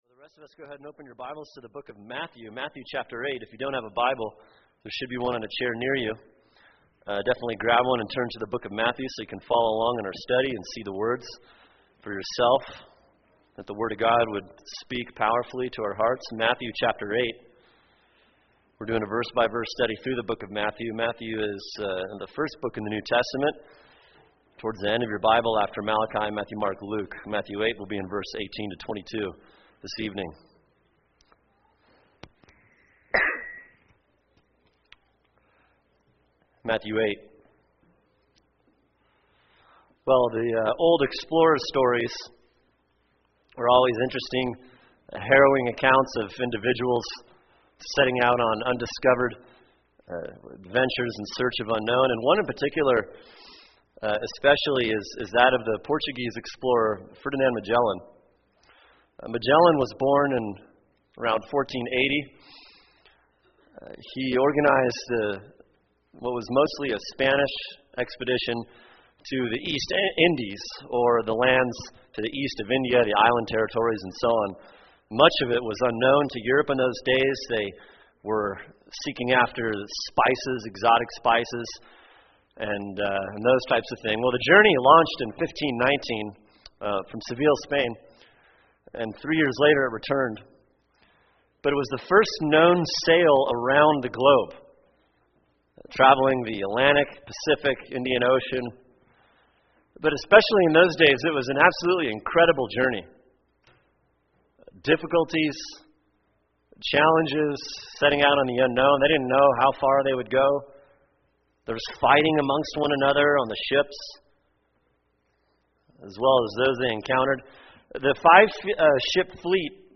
[sermon] Matthew 8:18-22 “How Christ Evangelizes” | Cornerstone Church - Jackson Hole